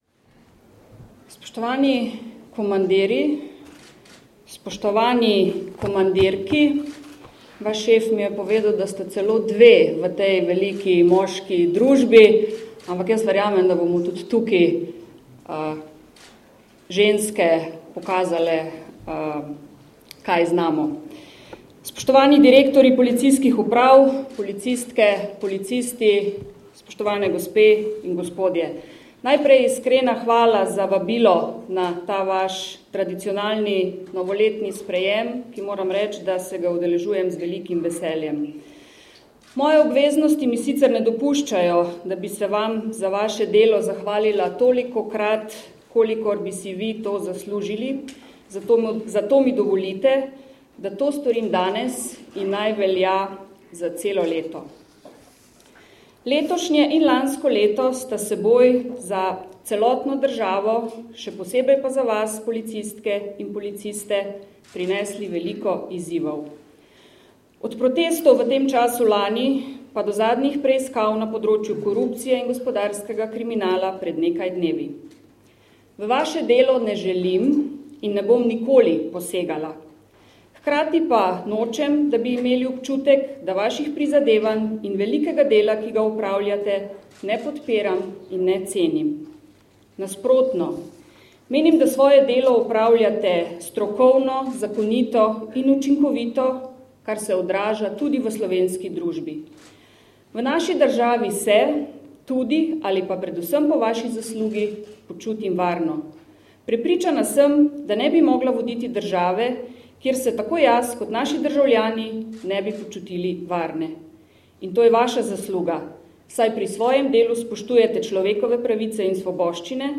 Zvočni posnetek nagovora predsednice vlade (mp3)